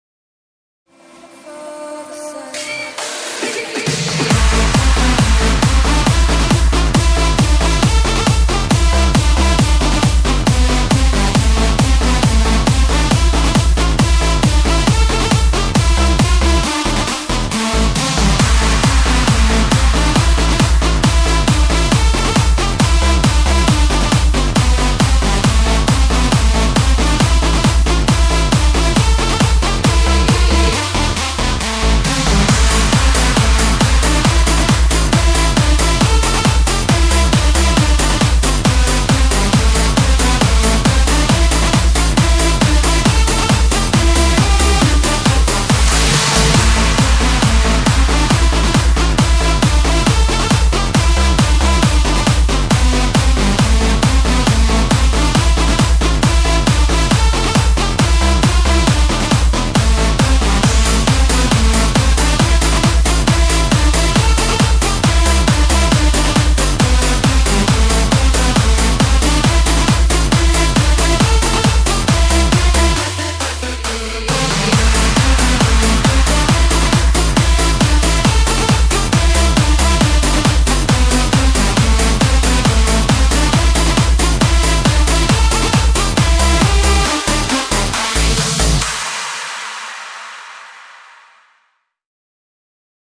136 bpm: 1,5 min